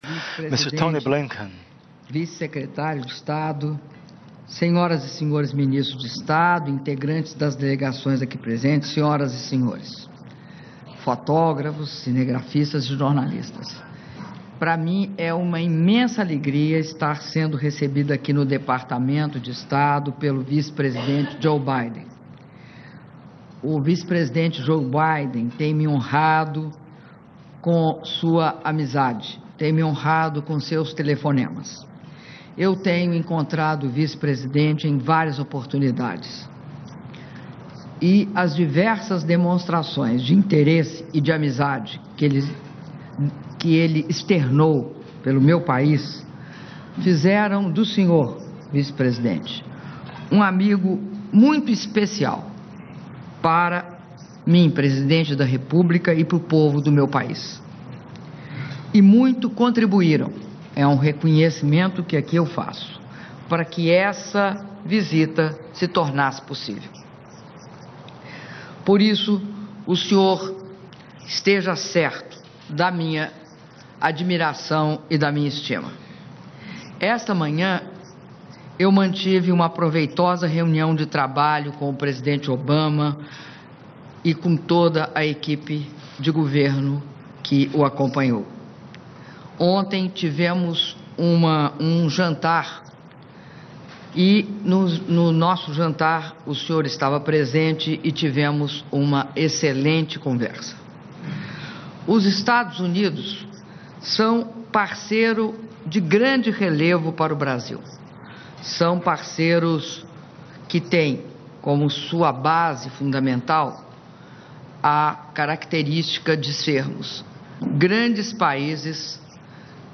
Áudio do brinde da presidenta da República, Dilma Rousseff, durante almoço oferecido pelo vice-presidente dos Estados Unidos da América, Joseph Biden (10min33s) - Washington/EUA